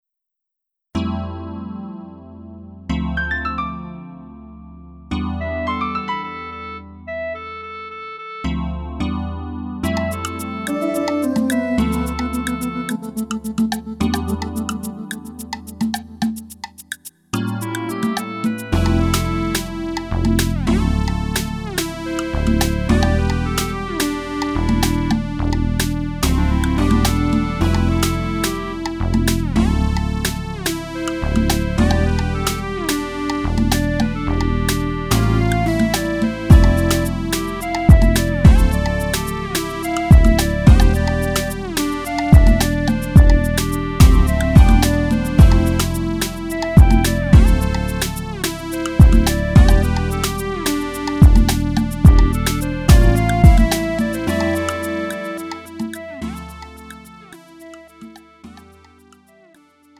음정 원키 3:19
장르 가요 구분